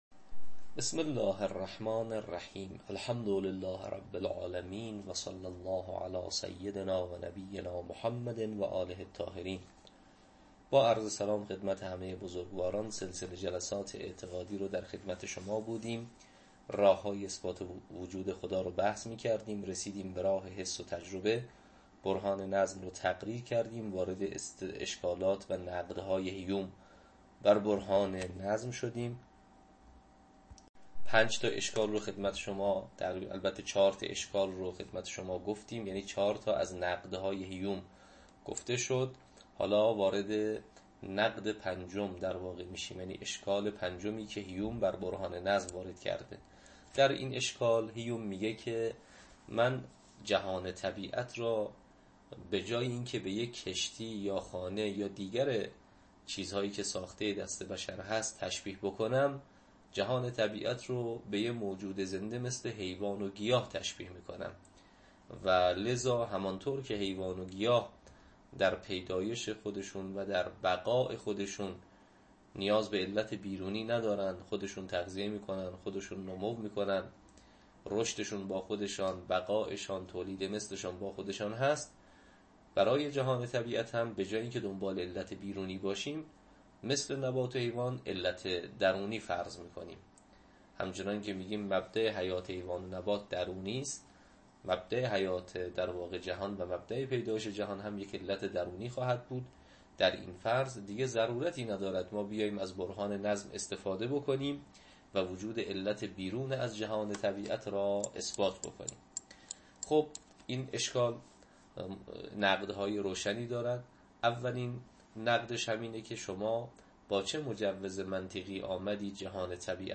تدریس عقاید استدلالی یک